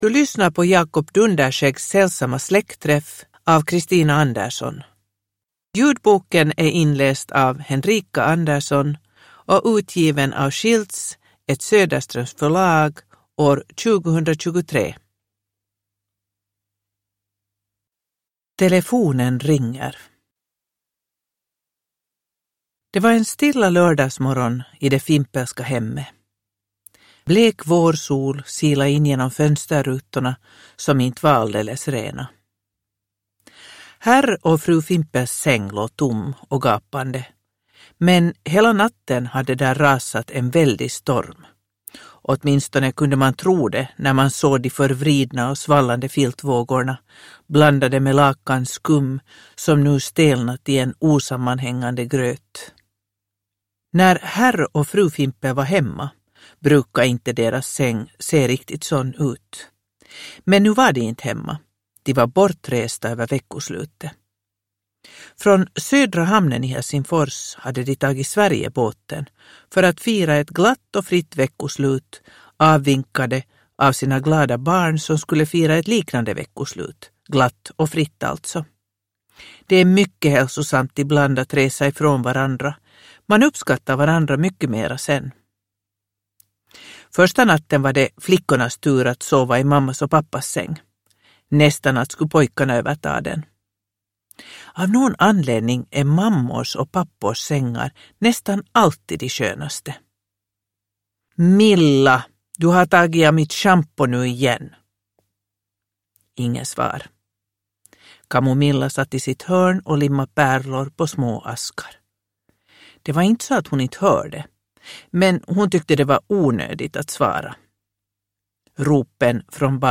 Jakob Dunderskäggs sällsamma släktträff – Ljudbok – Laddas ner